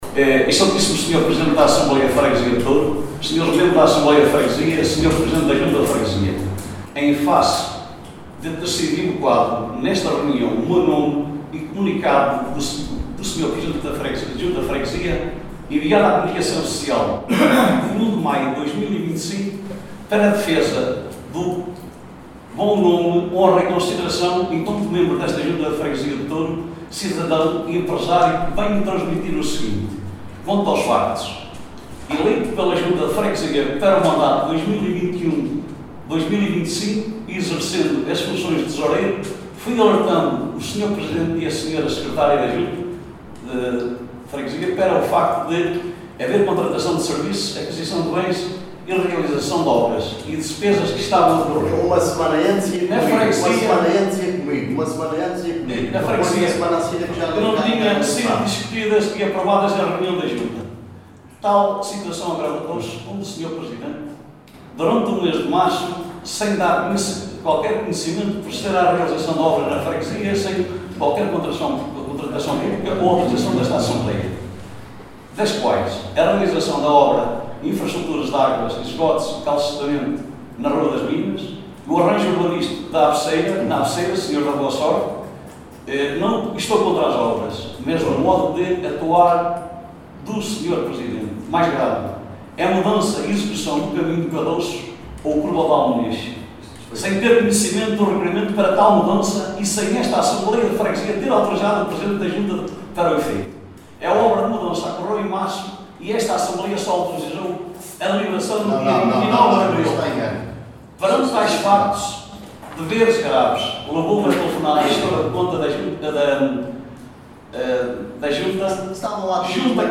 Jorge Morais, na sua intervenção falou do respetivo comunicado e do retiro de confiança nas funções Tesoureiro da Junta de Freguesia de Touro.